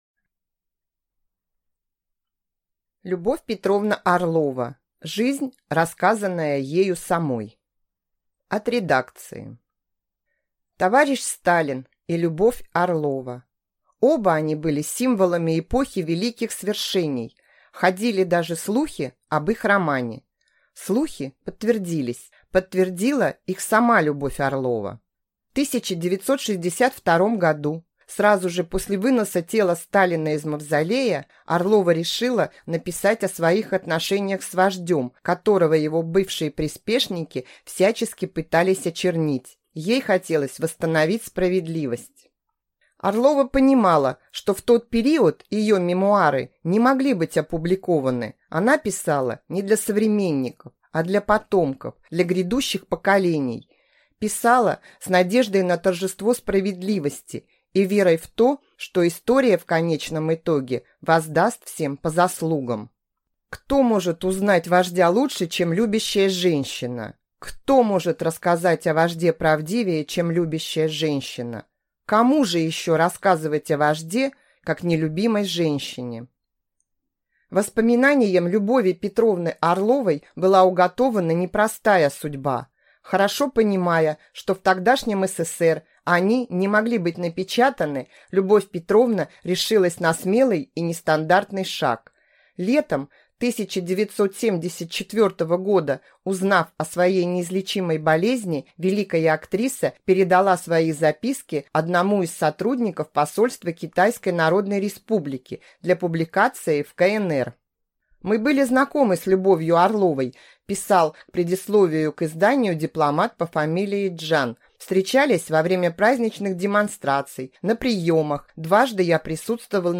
Аудиокнига Любовь Орлова.